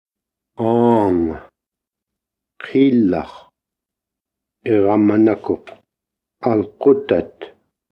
2. Аудиозаписи речи.